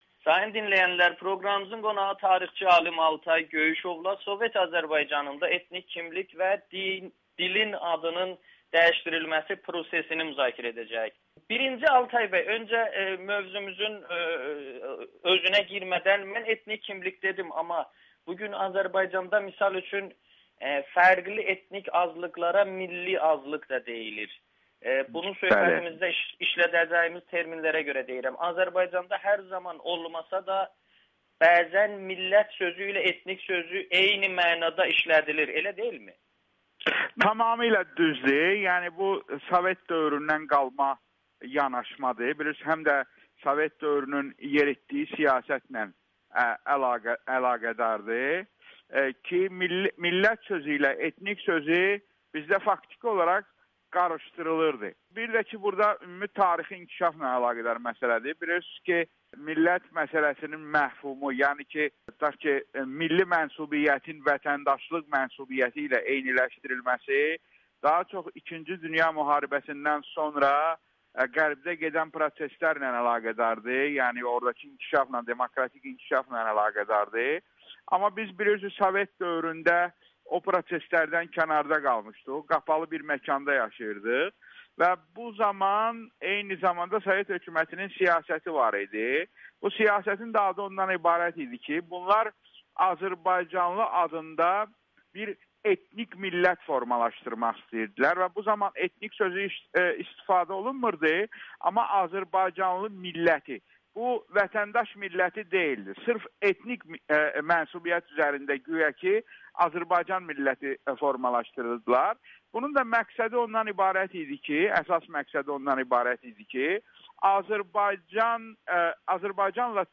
Tarixçi alim Amerikanın Səsinə müsahibədə həmin illərdə tətbiq edilən basqılar, o cümlədən Hüseyn Cavid kimi aydınların edamının dilin adının dəyişdirilməsi prosesi ilə əlaqədar olduğunu vurğulayır.